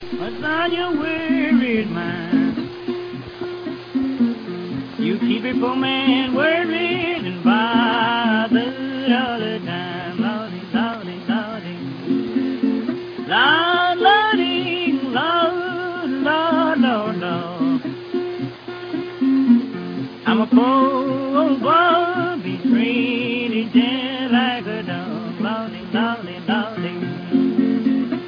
гитаре